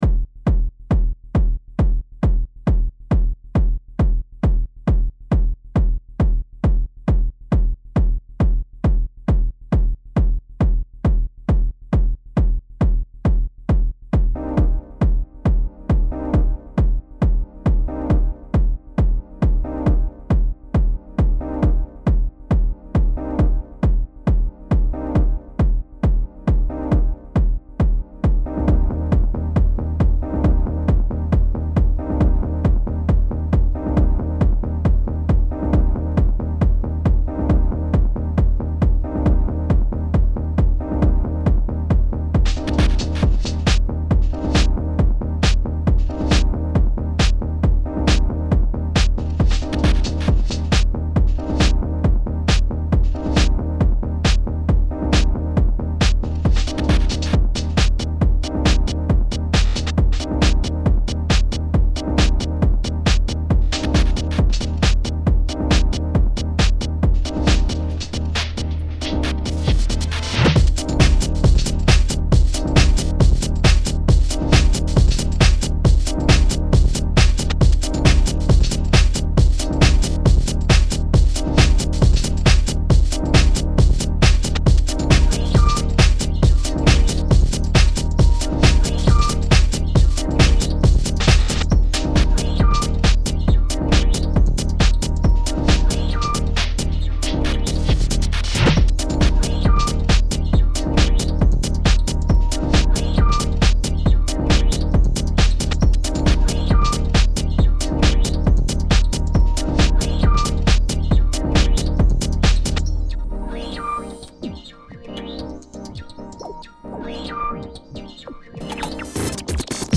intelligent house